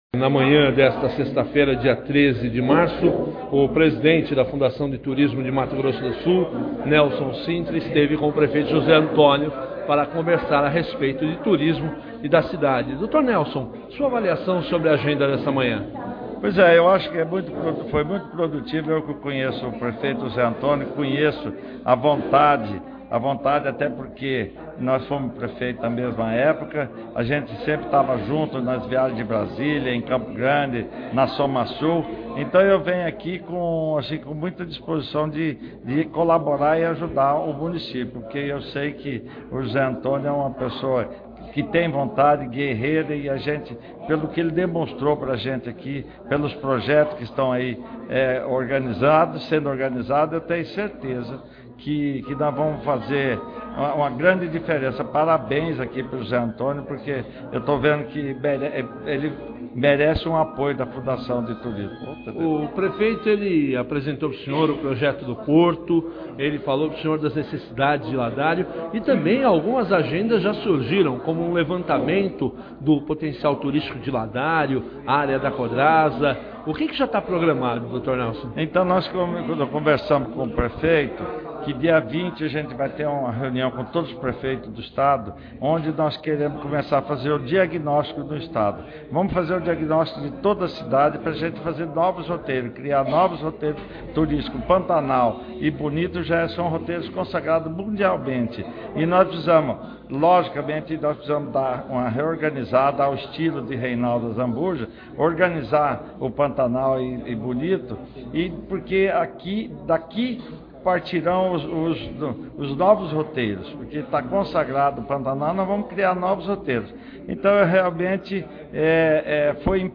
Entrevistas
PREFEITO JOSÉ ANTONIO E O DIRETOR-PRESIDENTE DA FUNDTUR, NELSON CINTRA